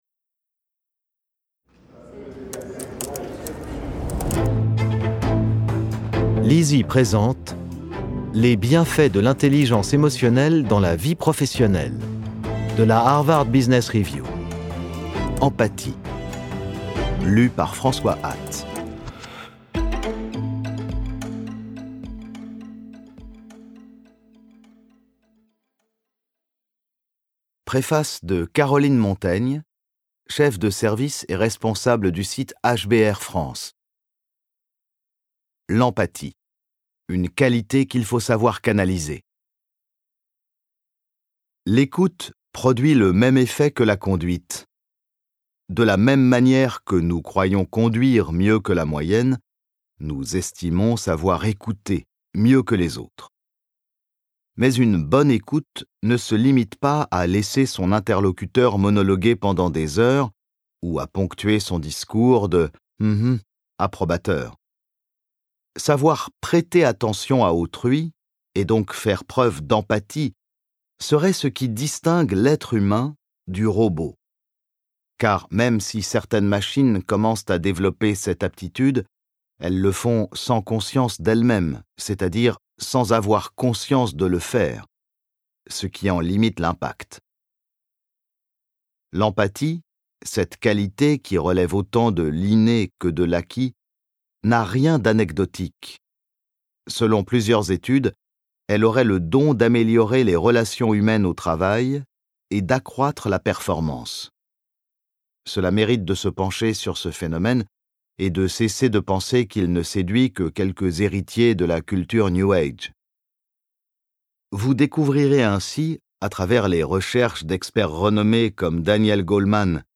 Extrait gratuit - Empathie de Harvard Business Review